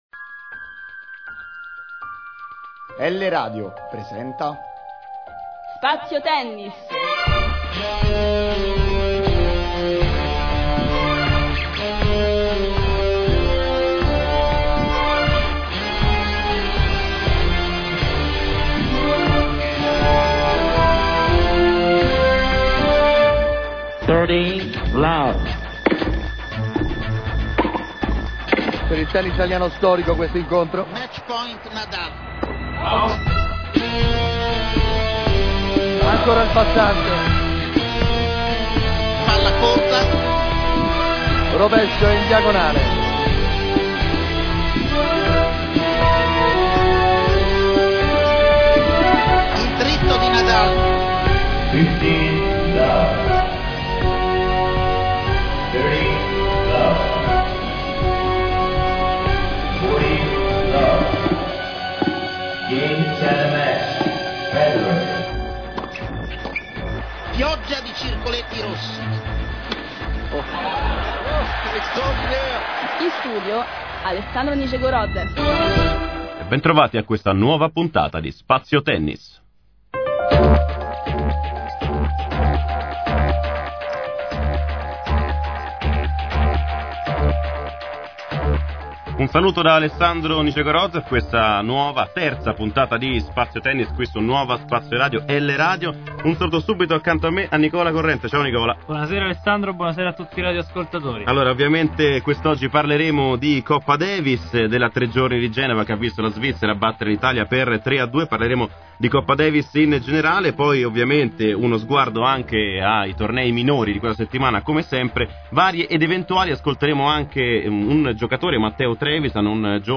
Nella prima parte troverete i risultati, le classifiche, intervista